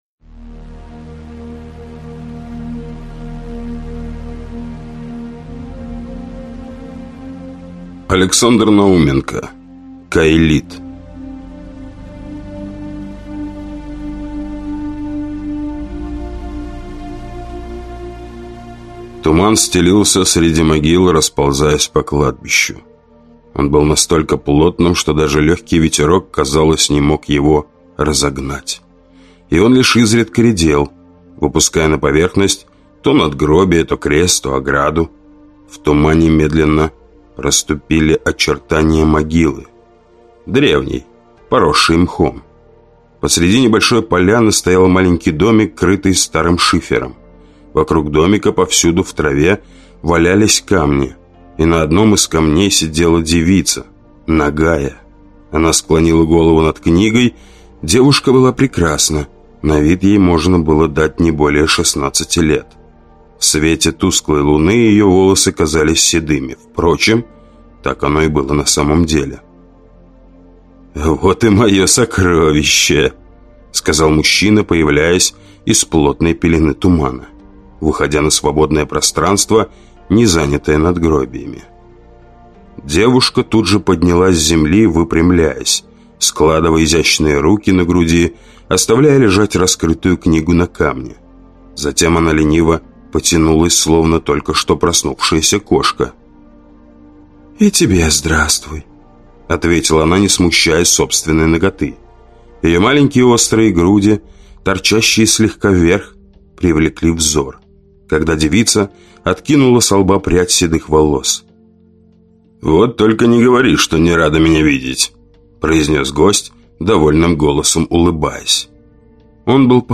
Aудиокнига Контининс
Читает аудиокнигу